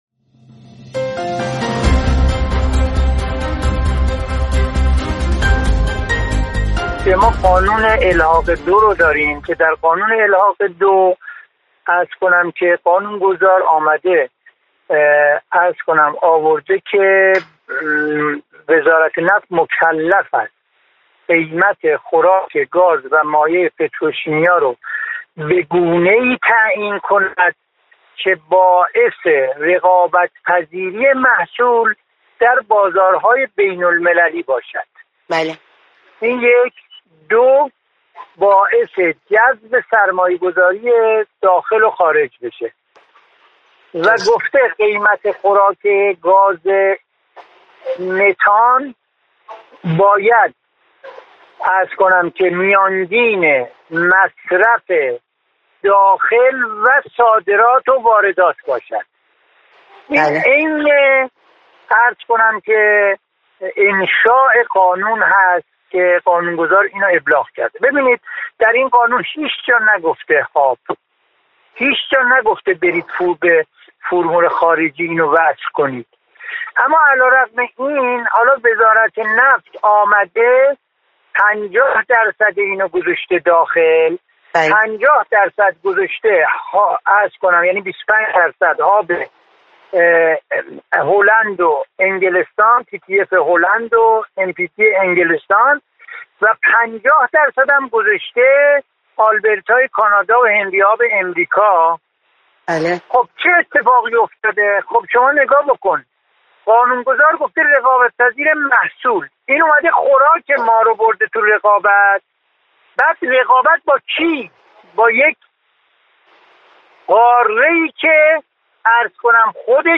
در گفتگویی تلفنی به دو سوال در این زمینه پاسخ داده: